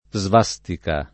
svastica
svastica [ @ v #S tika ] s. f.